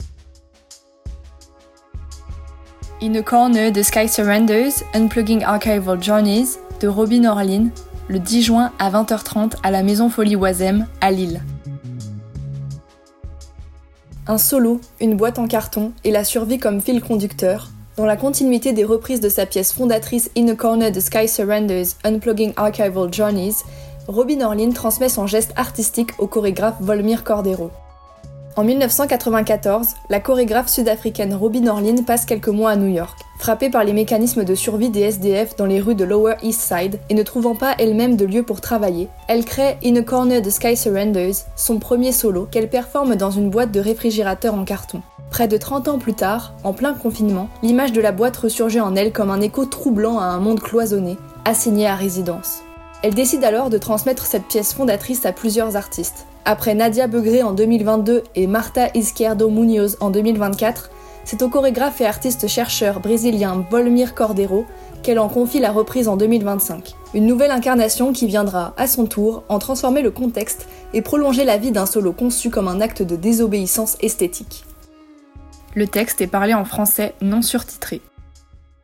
Version audio de la description du spectacle :